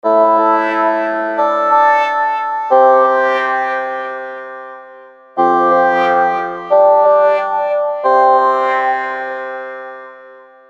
描述：果味循环......为当代的基督教曲目而写。
标签： 90 bpm Hip Hop Loops Synth Loops 1.79 MB wav Key : Unknown
声道立体声